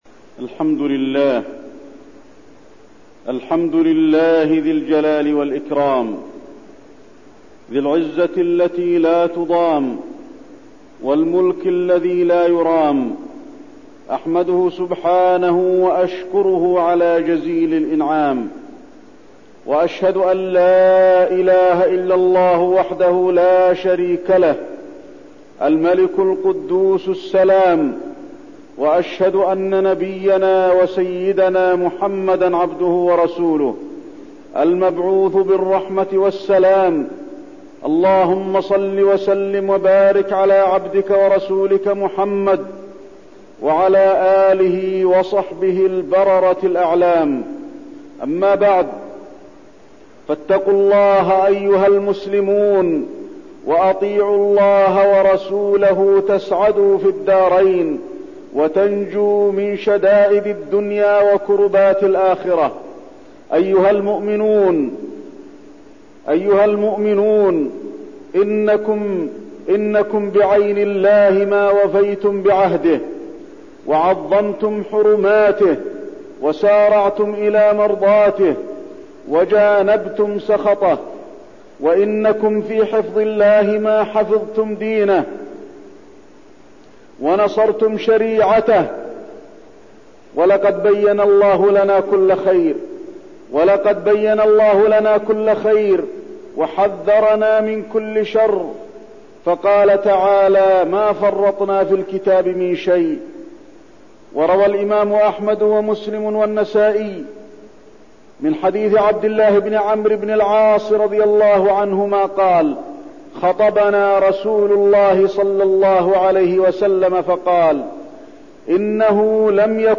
تاريخ النشر ١٨ صفر ١٤١١ هـ المكان: المسجد النبوي الشيخ: فضيلة الشيخ د. علي بن عبدالرحمن الحذيفي فضيلة الشيخ د. علي بن عبدالرحمن الحذيفي فتنة حاكم العراق